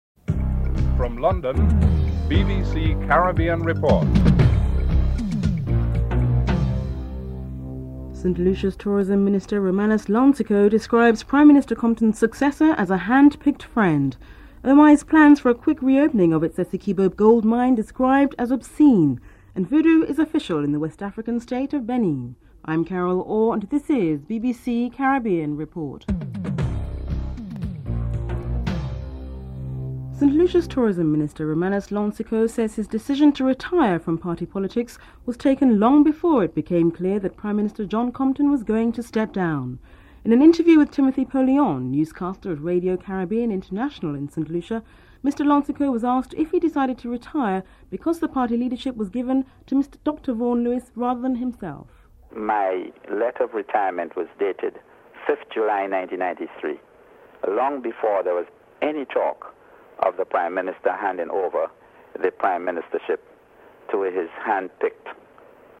1. Headlines (00:00-00:28)
3. British Home Affairs Minister Michael Howard is insisting that Dominica has received nothing in return for agreeing to house Saudi's dissident Mohammed Al-Massari. Home Affairs Minister Michael Howard is interviewed and John Humphrys reports (05:48-08:22)